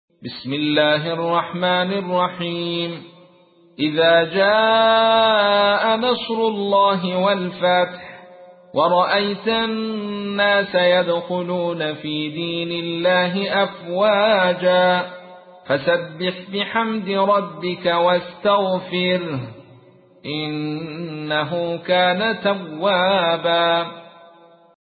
تحميل : 110. سورة النصر / القارئ عبد الرشيد صوفي / القرآن الكريم / موقع يا حسين